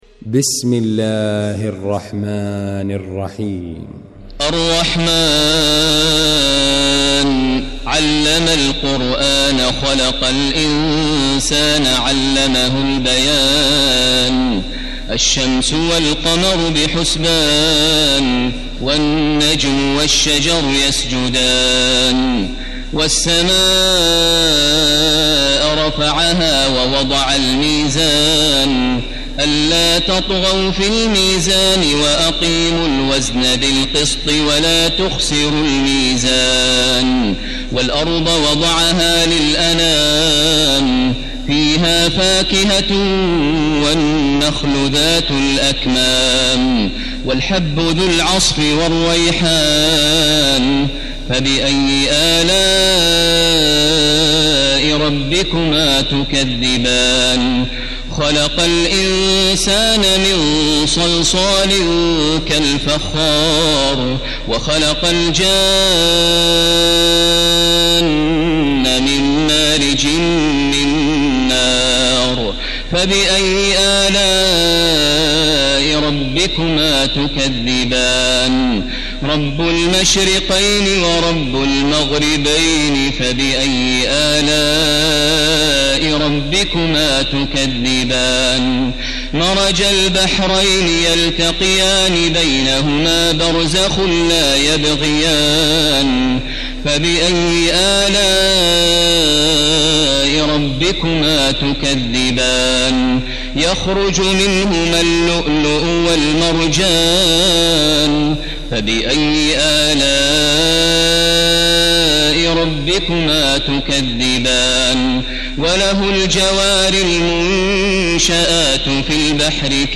المكان: المسجد الحرام الشيخ: فضيلة الشيخ ماهر المعيقلي فضيلة الشيخ ماهر المعيقلي الرحمن